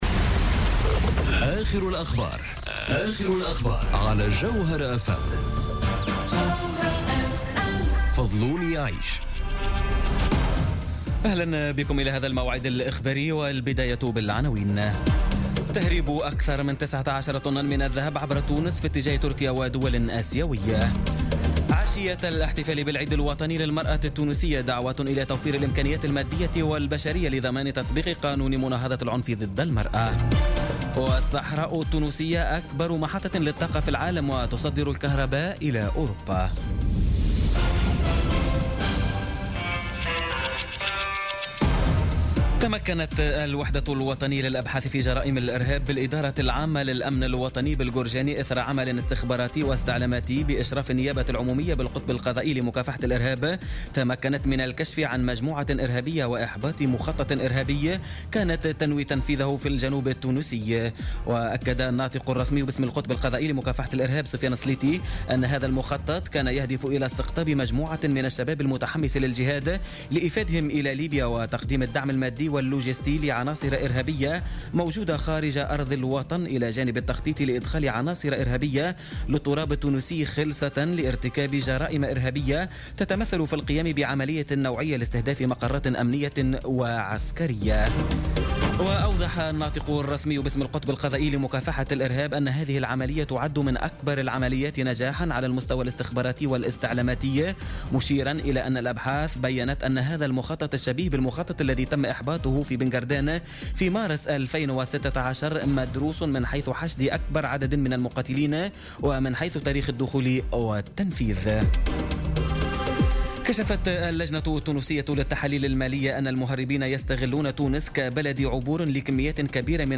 نشرة أخبار السابعة مساء ليوم السبت 12 أوت 2017